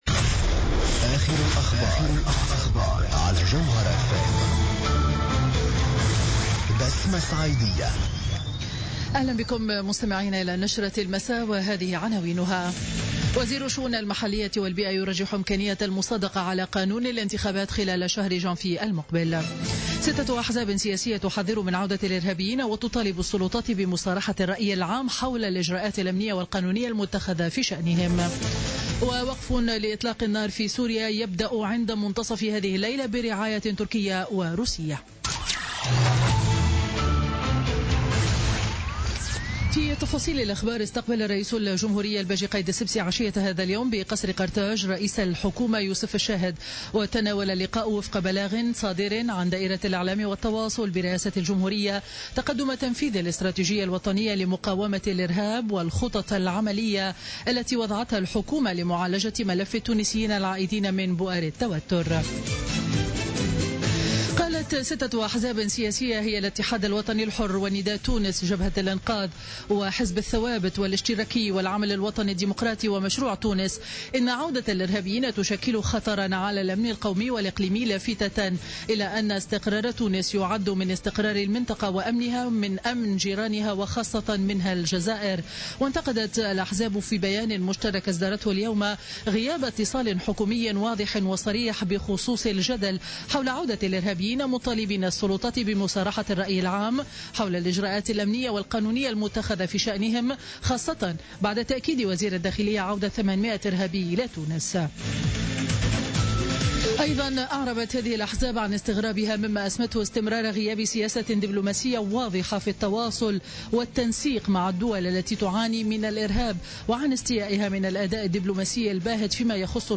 نشرة أخبار السابعة مساء ليوم الخميس 29 ديسمبر 2016